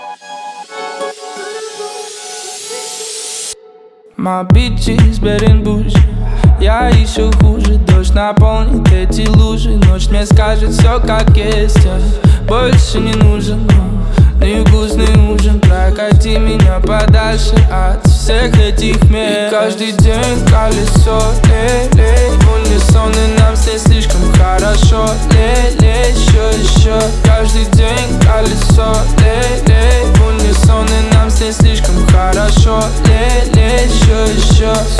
Жанр: Соул / R&b / Русские